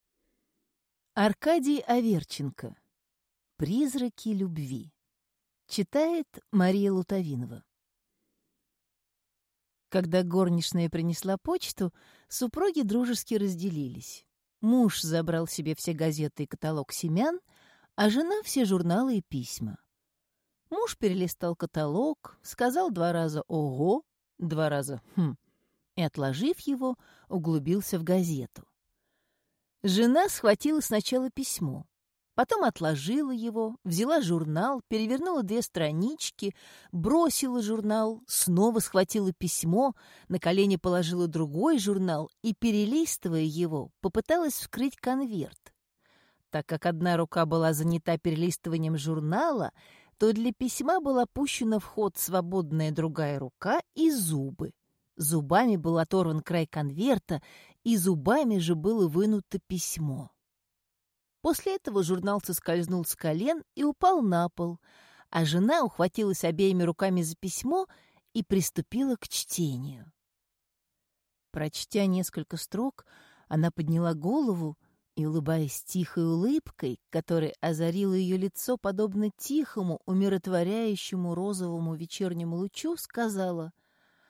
Аудиокнига Призраки любви | Библиотека аудиокниг